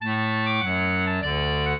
clarinet
minuet0-2.wav